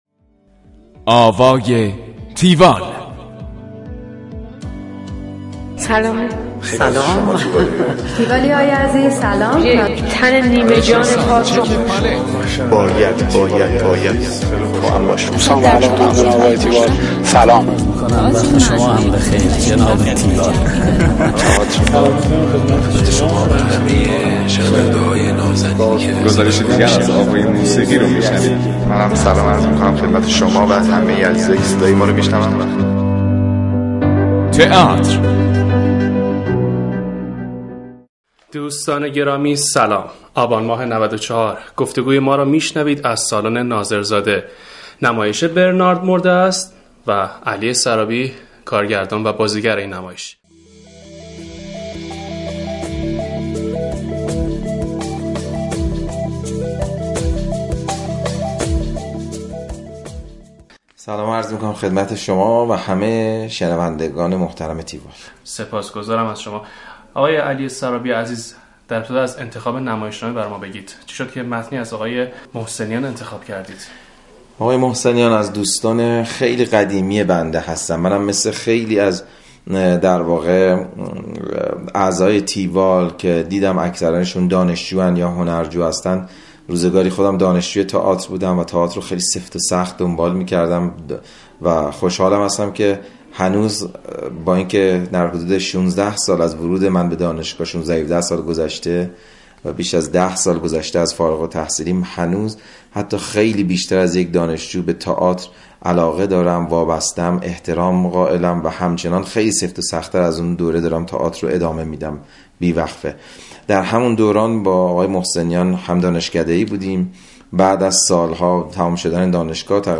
گفتگوی تیوال با علی سرابی